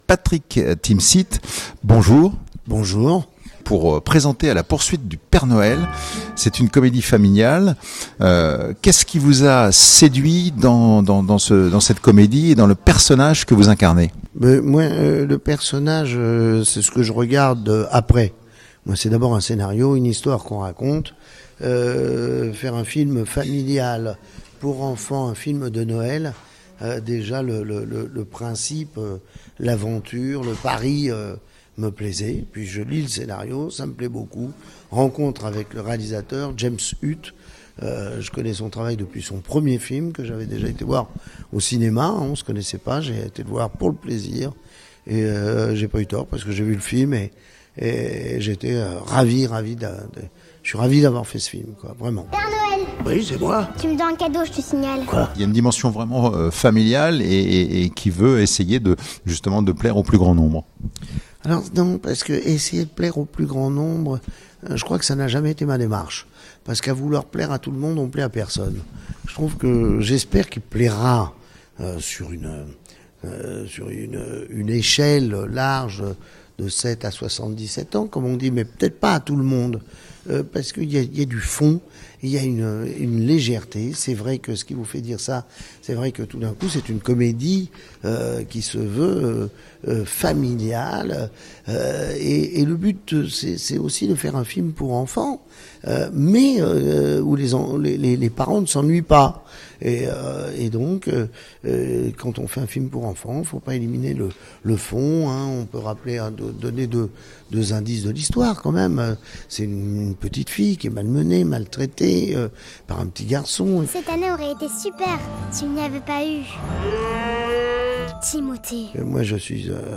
Mais ce qui a d’abord séduit l’acteur, c’est bien l’histoire : « Je commence toujours par le scénario », confie-t-il